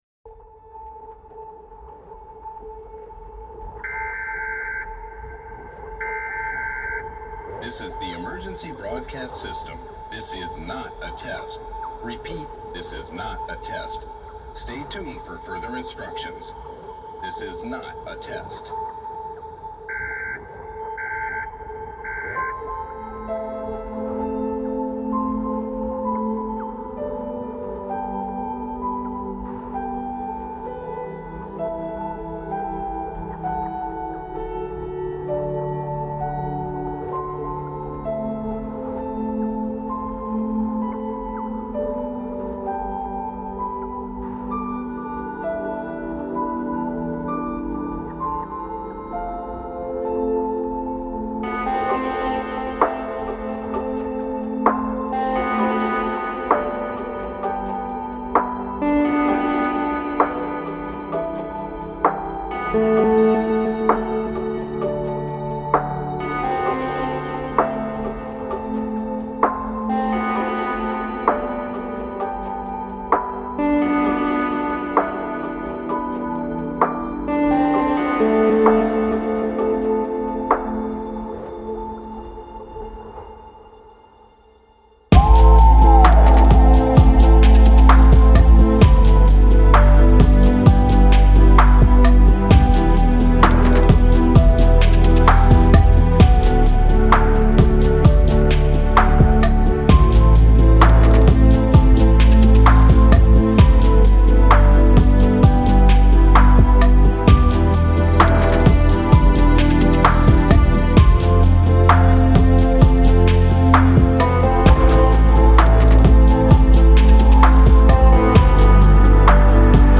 энергичная поп-рок песня